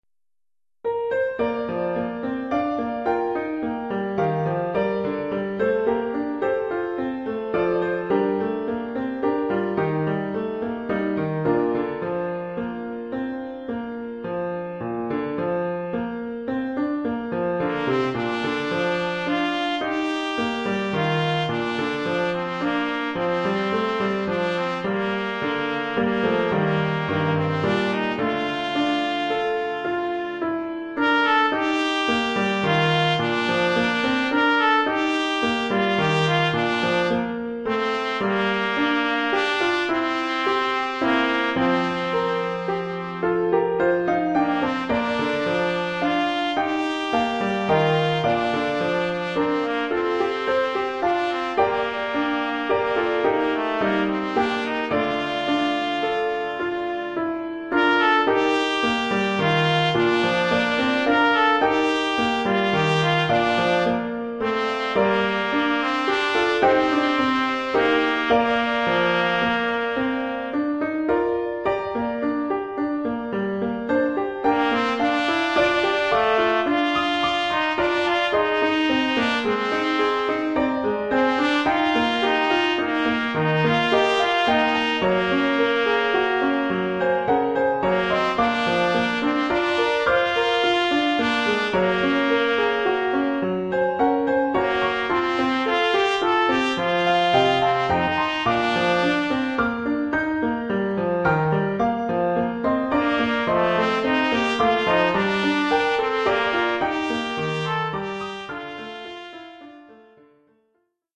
Oeuvre pour trompette ou cornet ou bugle et piano.